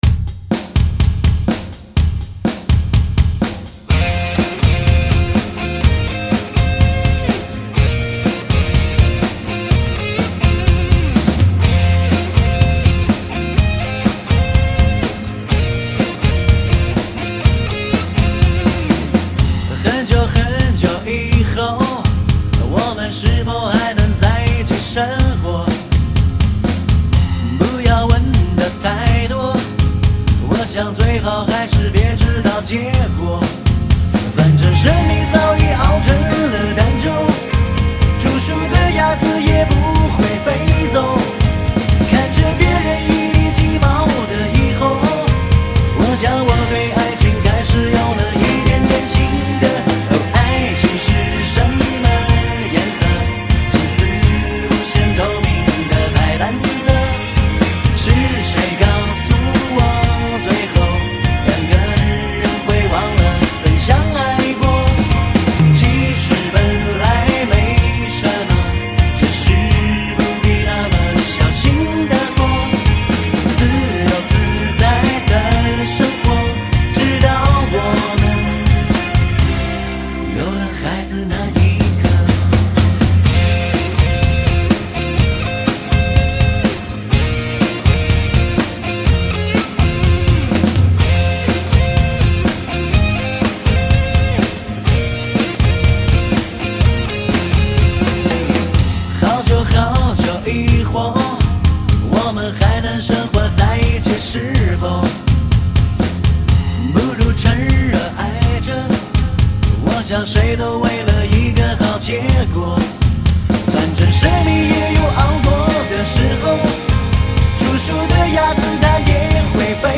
架子鼓